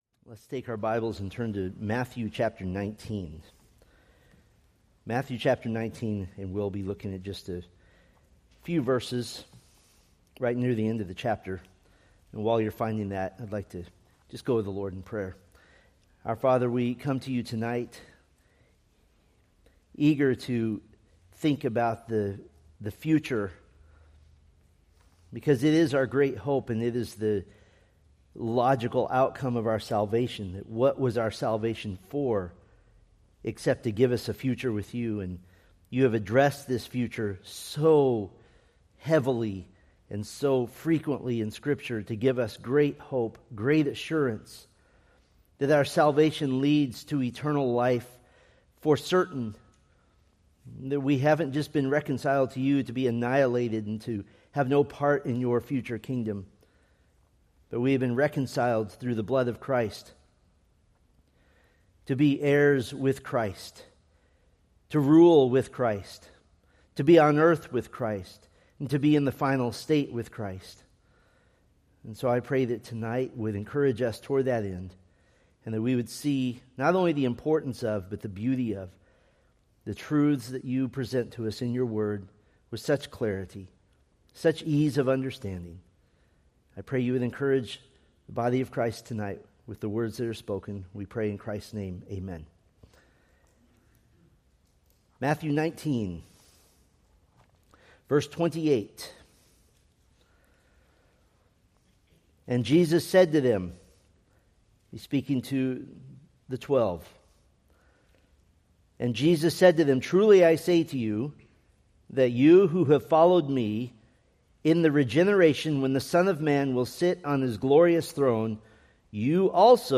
Preached September 14, 2025 from Selected Scriptures